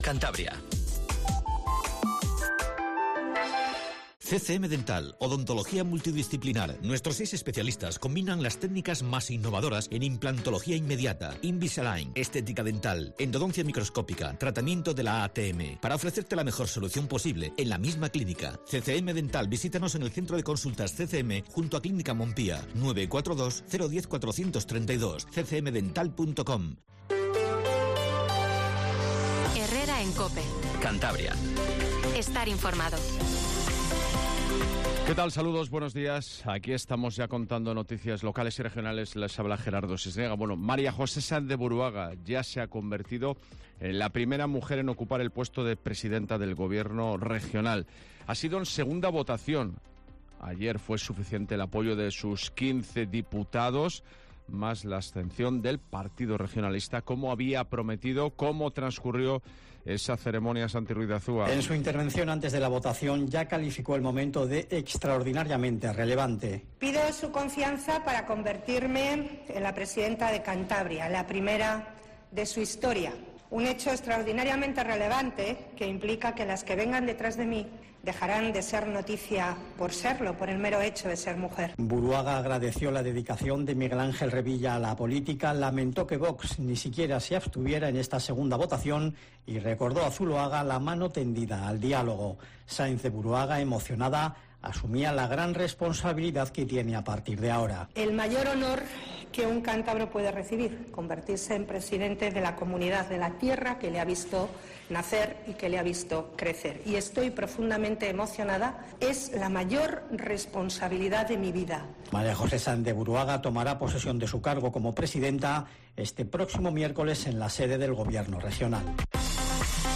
Informativo Matinal Cope 07:20